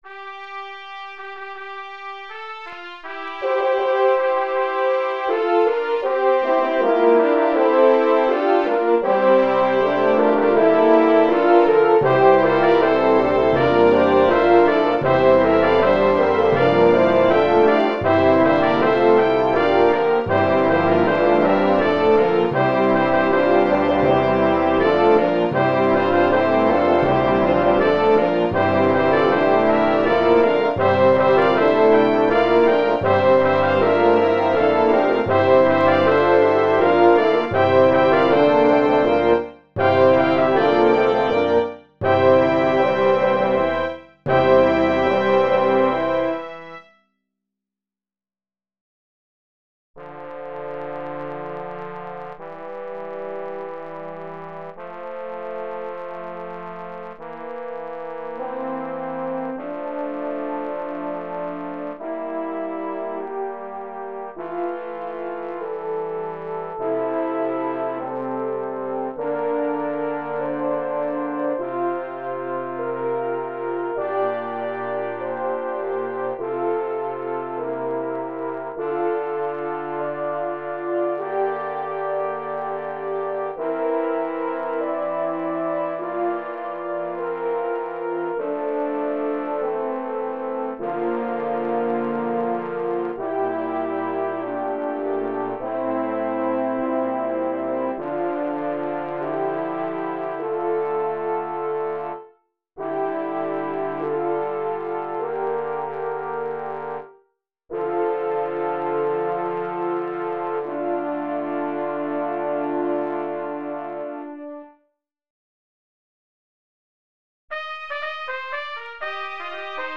A dramatic setting in 4 sections
for Baritone Solo, 4-part mixed choir (SATB), and orchestra
Voicing/Instrumentation: SATB , Cantata/Choir Full Program/Play , Orchestra See more from Ralph S. Gardner II .